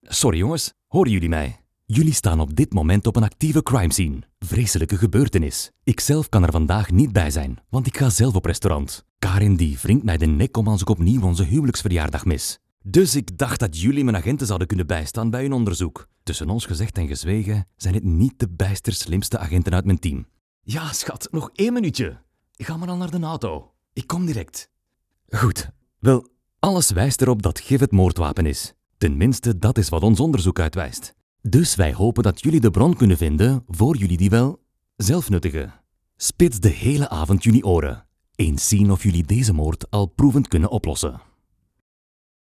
Male
Corporate, Engaging, Friendly, Reassuring, Warm
A warm, friendly and confident voice and clear language to create the right atmosphere and the perfect tone.
FLEMISH Reel.mp3
Microphone: SENNHEISER MKH 416 /// Neumann TLM102 Audio-Technica 2035
Audio equipment: Audient ID4 MKII interface, New Macbook Pro 14″ 2022, Acoustically treated room